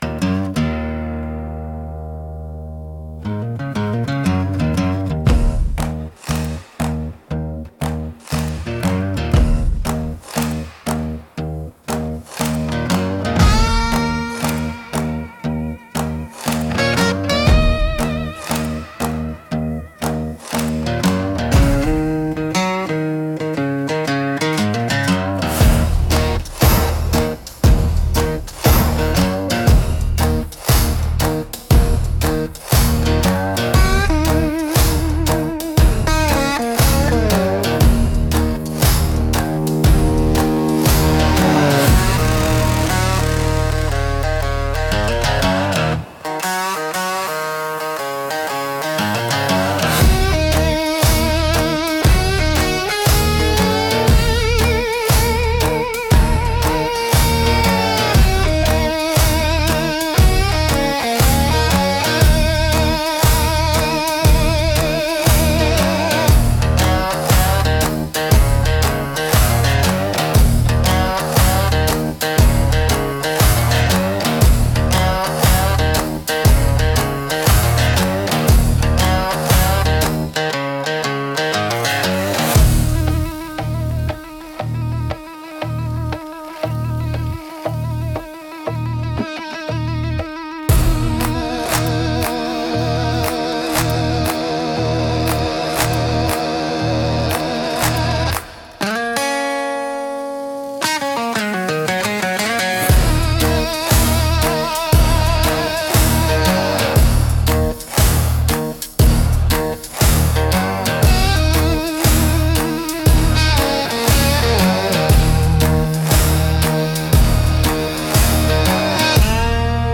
Deep Bluesy Country